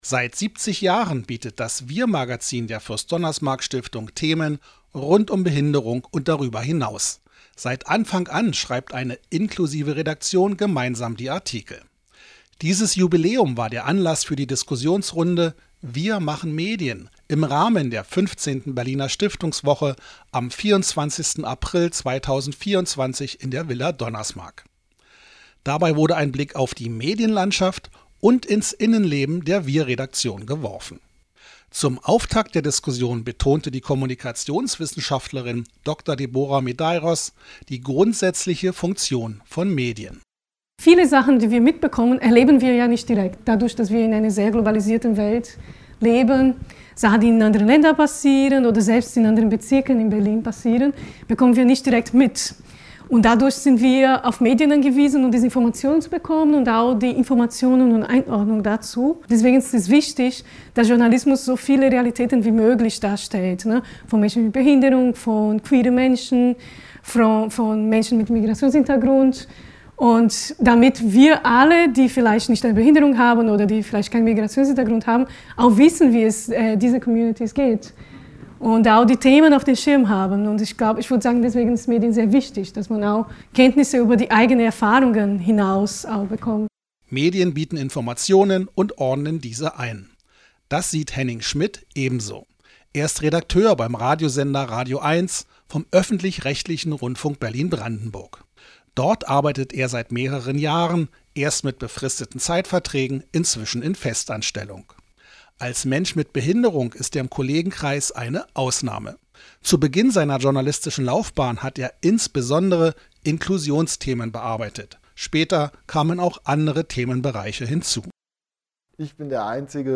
Hörbericht WIR machen Medien
Podcast zur Diskussion in der Villa Donnersmarck